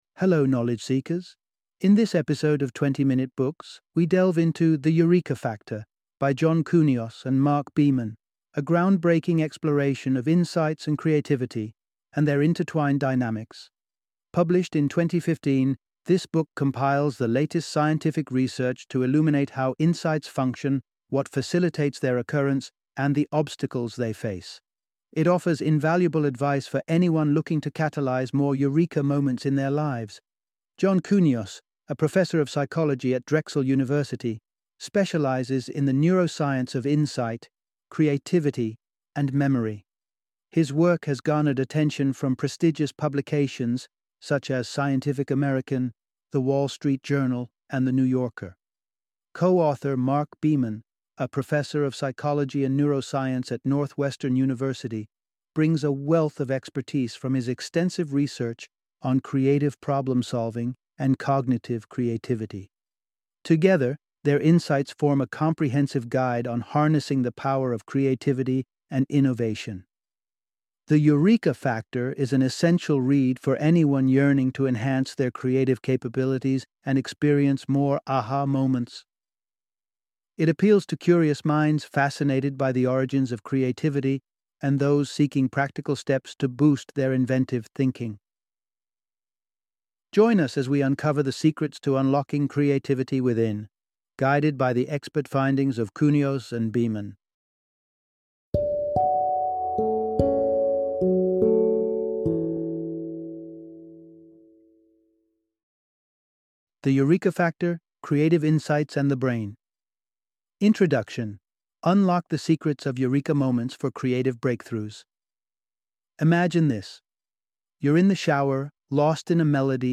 The Eureka Factor - Audiobook Summary